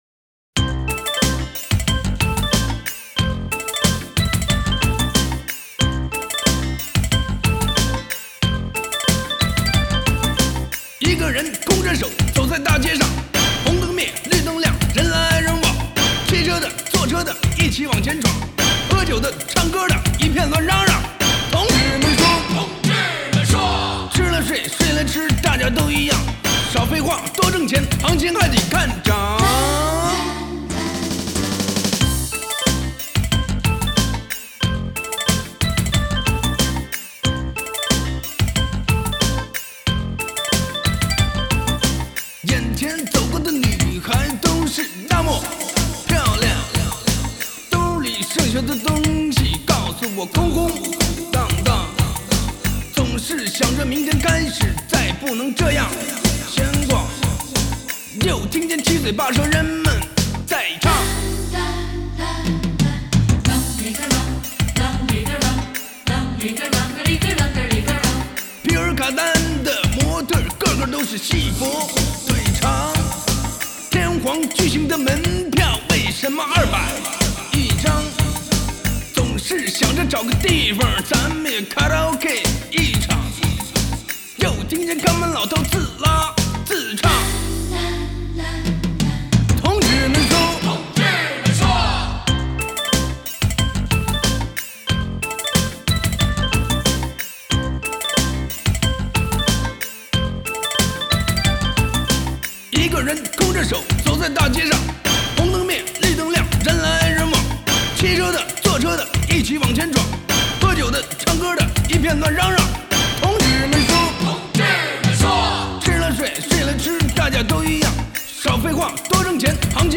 嘻哈/说唱